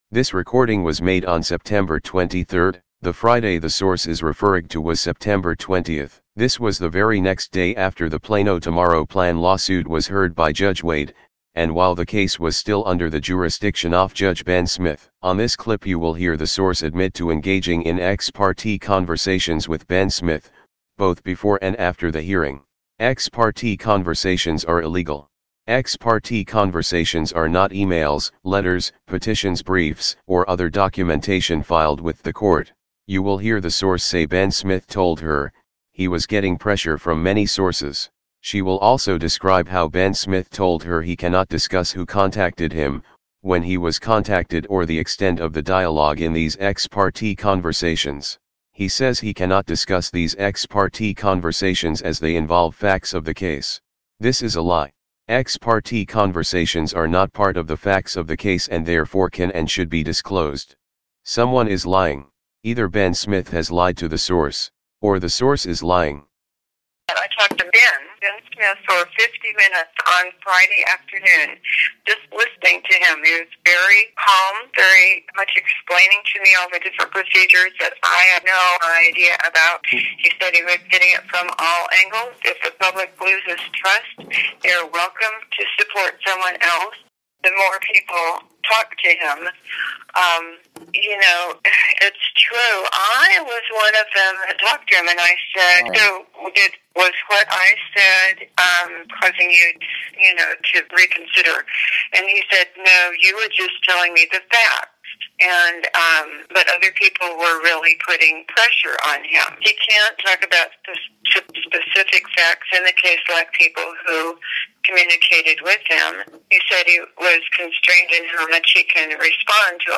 This Ex Parte conversation was had the day after the Plano Tomorrow Plan hearing while the case was still under Smith's jurisdiction. On the clip you will hear about Ben Smith's Ex Parte conversations from a participant. You will hear why Ben Smith violated his oath by allowing personal feelings to negatively impact his ability to be an impartial judge, follow his oath and avoid the appearance of judicial impropriety.